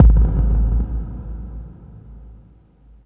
45 BD 2   -L.wav